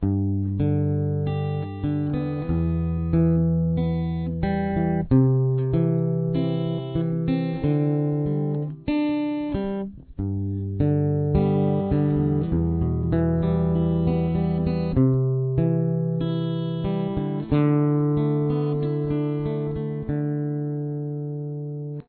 Rhythm Guitar